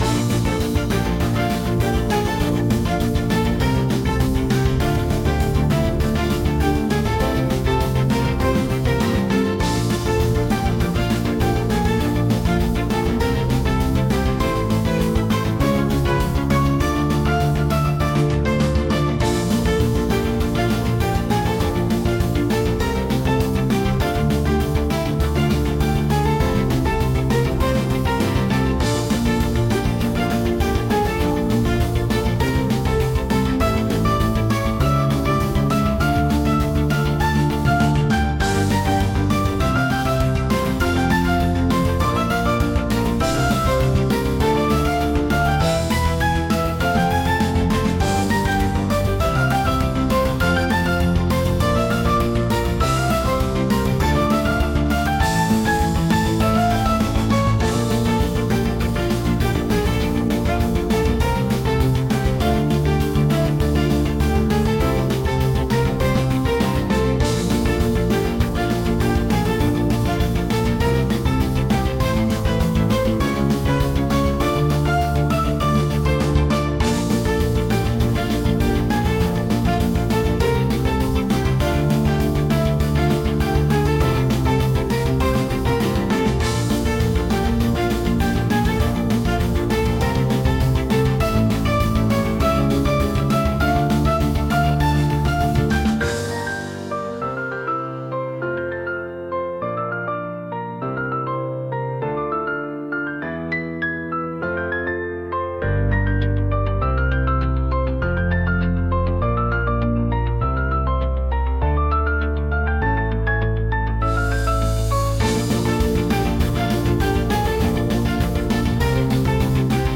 激しい